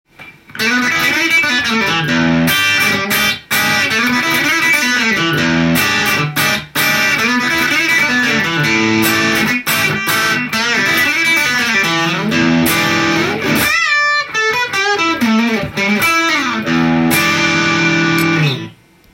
チューブスクリーマ―をモデファイ（改造）して制作されています。
アンプを選ばずにスティービーレイヴォーン系の音が出るようで
ジャズ系トランジスタ ギターアンプ「ポリトーン」に繋いで
真空管アンプに繋いだようなガラスに合たったようなリアルな音がします。